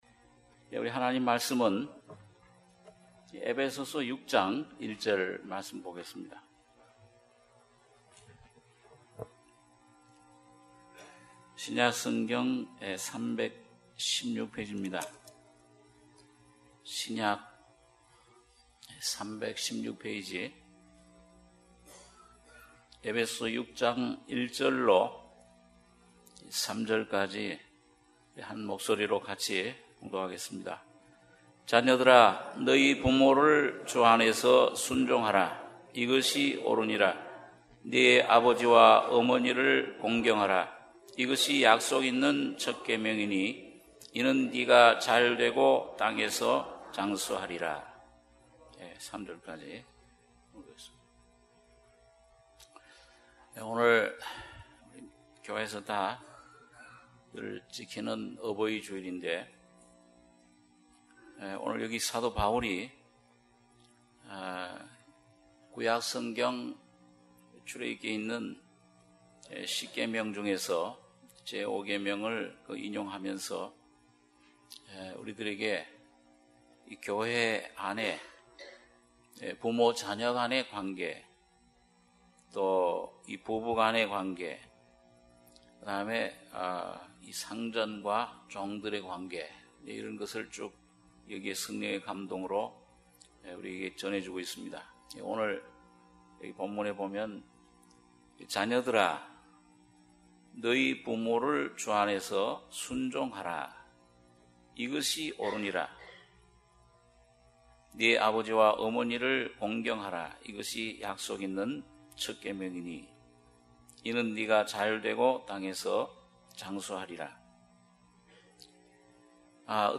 주일예배 - 에베소서 6장 1절~3절